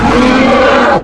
enrage.wav